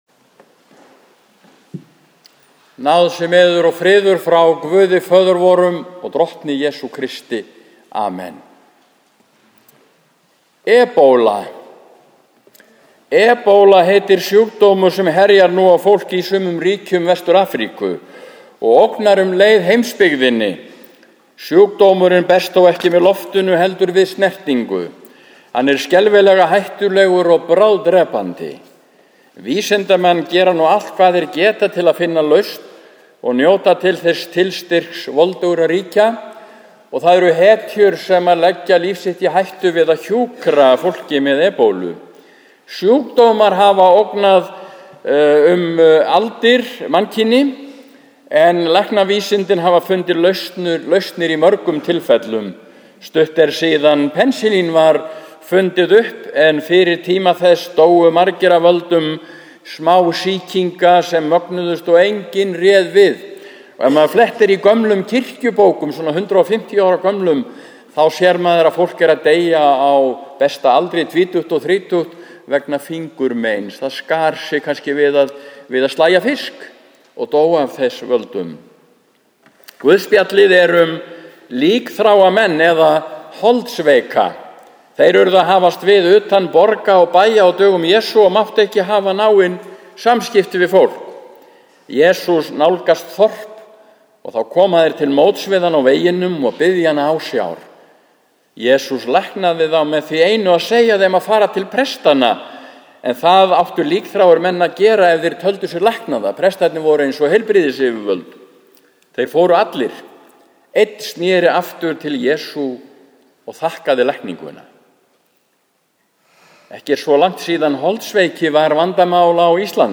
Prédikun í Neskirkju sunnudaginn 21. september 2014 – 14. sd. e. trin